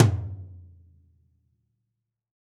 MidTom Zion.wav